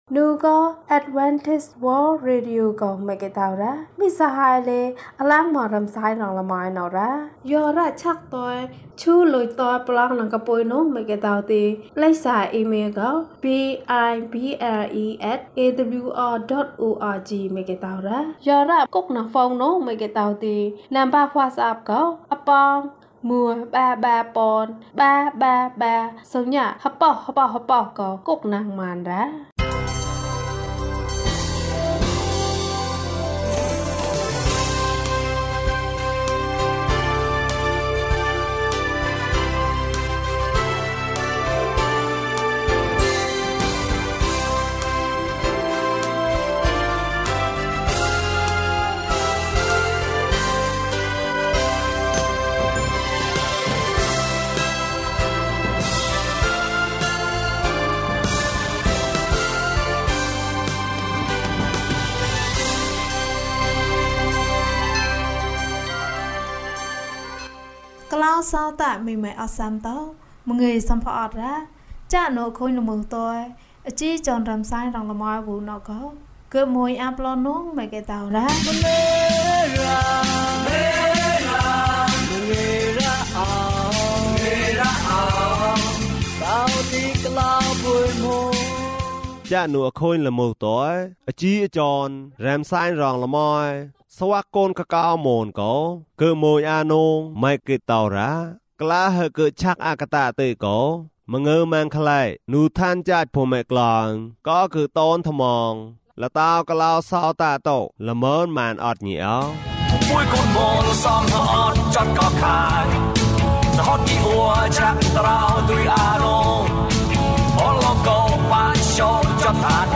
ခရစ်တော်ထံသို့ ခြေလှမ်း ၂၃။ ကျန်းမာခြင်းအကြောင်းအရာ။ ဓမ္မသီချင်း။ တရားဒေသနာ။